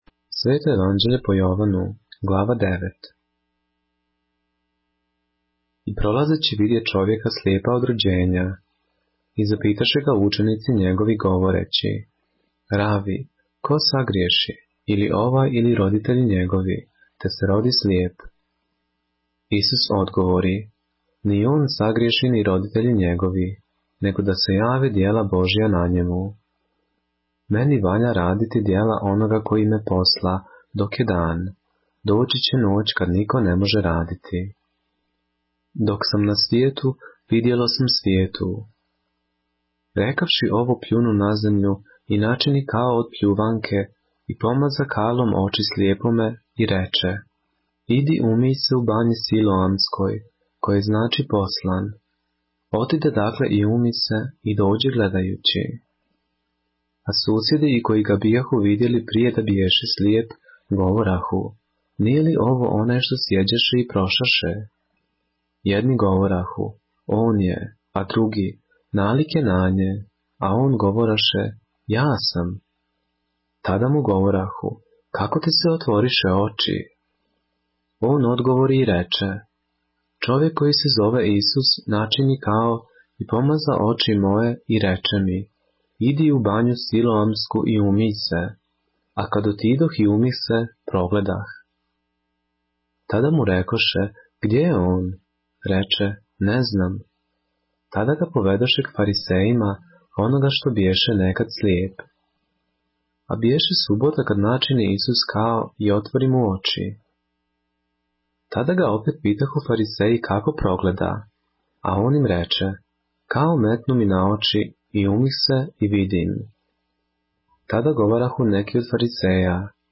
поглавље српске Библије - са аудио нарације - John, chapter 9 of the Holy Bible in the Serbian language